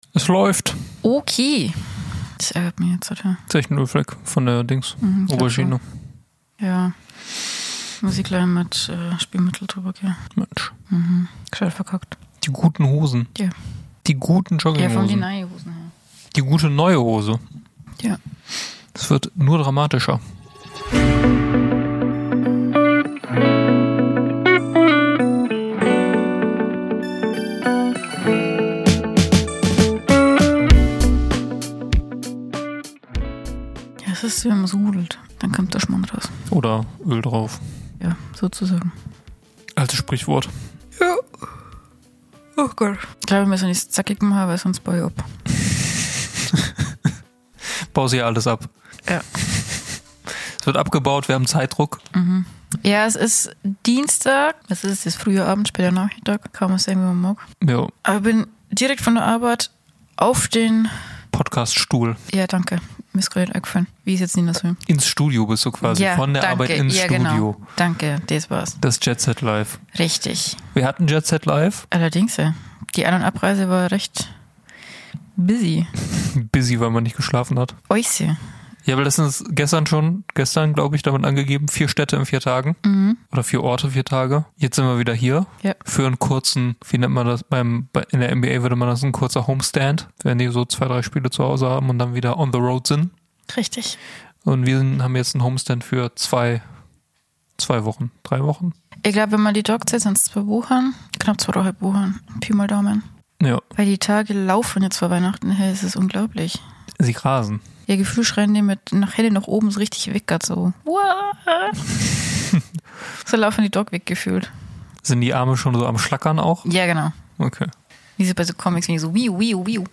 In unseren unendlichen Mühen nehmen wir euch mit auf eine weitere Reise nach Bayern, wo die Quatsch & Gelaber Reporter live beim Perchten-Spektakel anwesend waren und 50% der Besatzung als Korrespondent fungieren.